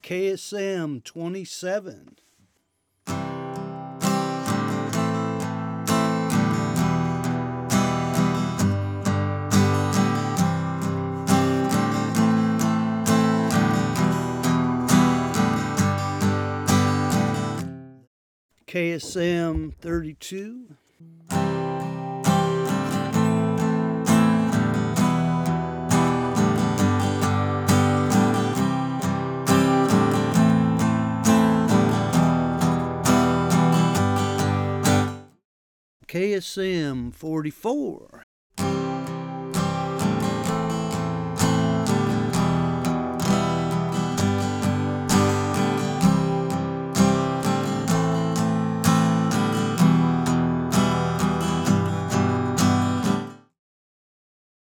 Acoustic and Vocal comparison in my own below average HR room showed me interesting things.
1) as I did a year ago between the 44 and 27, same capsule except 44 is dual....Im in Cardioid and the 27 sounds the same.
2) The 32 is a different breed which to me doesnt have the sparkle as the other two but it seems to be more talked about and popular,
3) Compared to my SM7 and SM58 its a different game, more separation and less pickup of the room and less clarity for the dynamics but at least until mixdown when the eq and plugs come out).